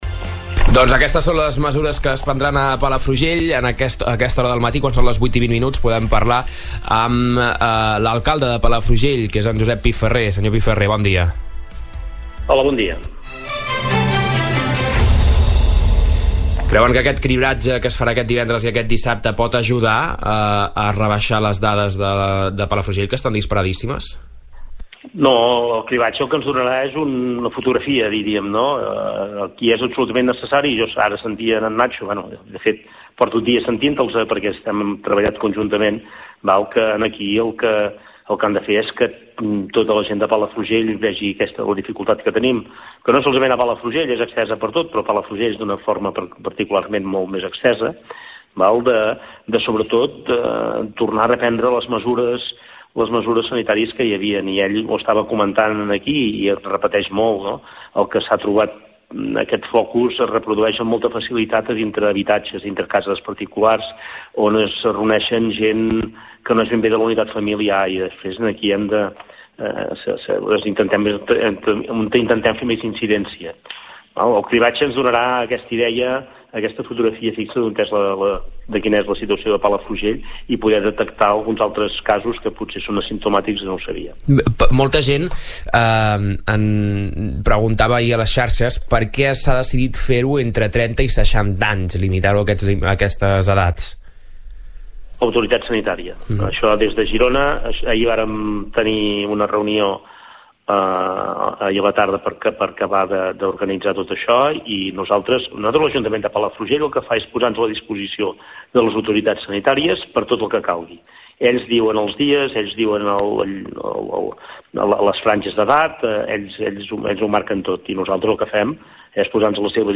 Al Supermatí d’avui hem entrevistat Josep Piferrer, alcalde de Palafrugell perquè ens informi sobre les noves mesures que es prendran al municipi.